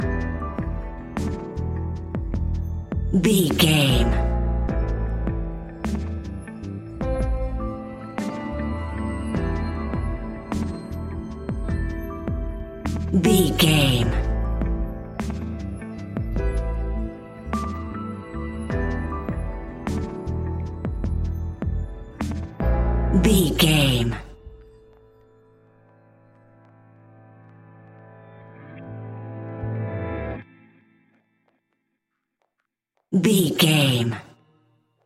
In-crescendo
Thriller
Uplifting
Ionian/Major
A♭